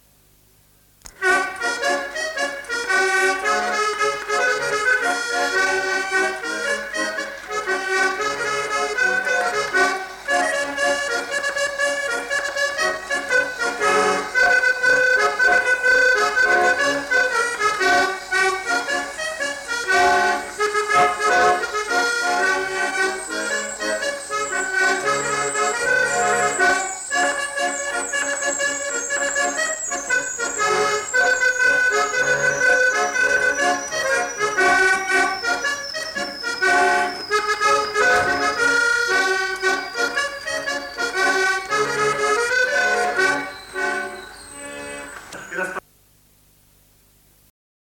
Lieu : Mas-Cabardès
Genre : morceau instrumental
Instrument de musique : accordéon diatonique
Notes consultables : Le joueur d'accordéon n'est pas identifié. Il joue sans doute une figure de quadrille.